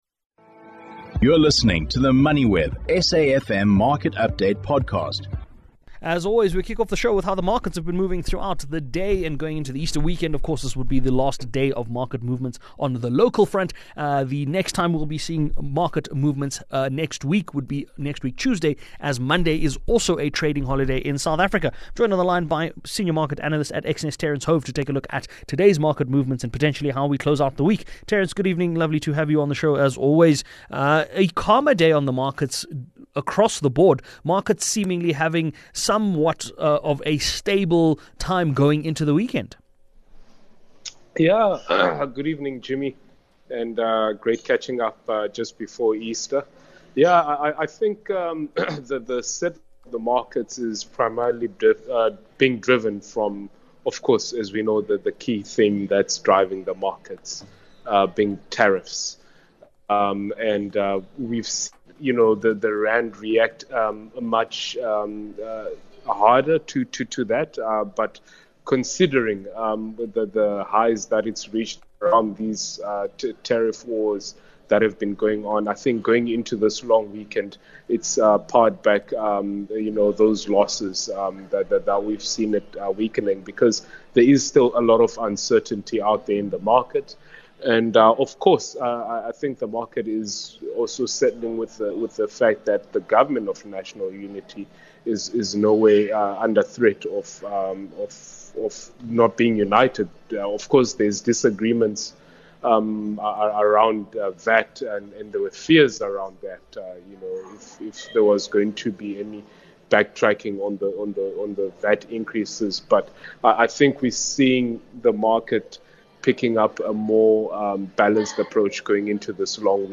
The programme is broadcasted Monday to Thursday nationwide on SAfm (104 107fm), between 18:00 and 19:00.